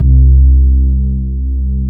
FRETLESSC3-L.wav